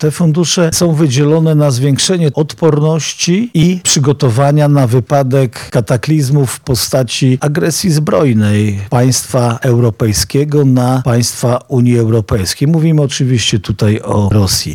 Stawiarski 1 – mówi Jarosław Stawiarski, marszałek województwa lubelskiego